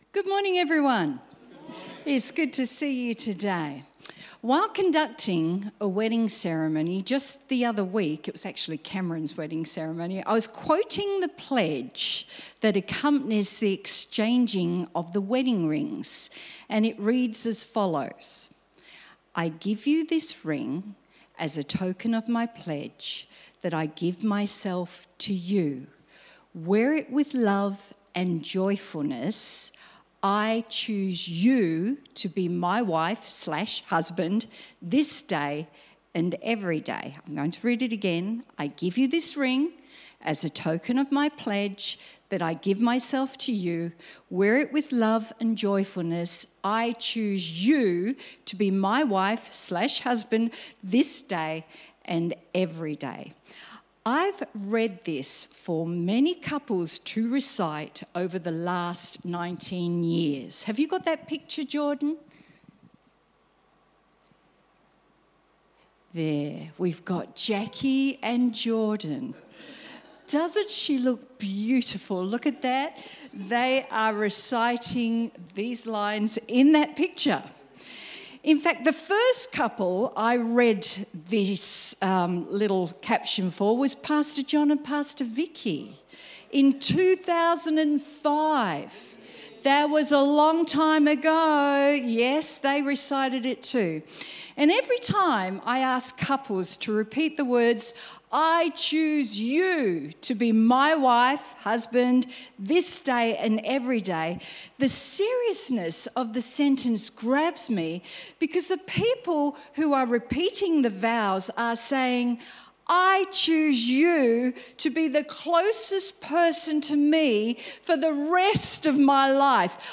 Sermon Transcript While conducting a wedding ceremony, just the other week, I was quoting the pledge that accompanies the exchanging of the wedding rings and it reads as follows.